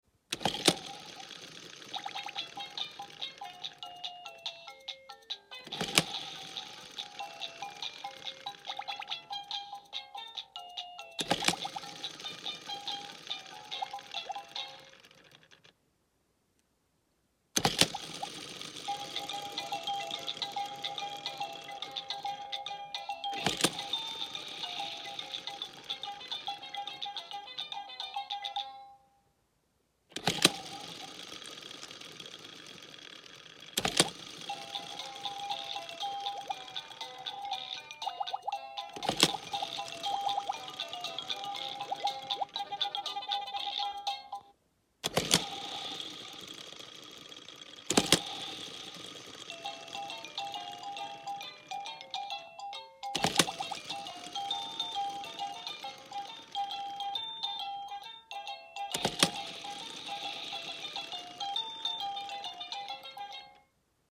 Bright Starts baby colourful spinning sound effects free download